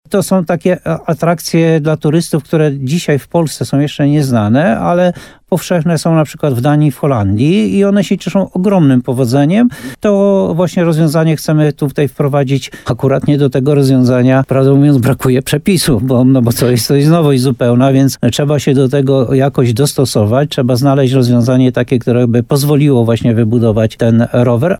Jak powiedział burmistrz Jan Golba, będzie to bardziej zaawansowana forma tyrolki, która zostanie wyposażona w mechanizm roweru.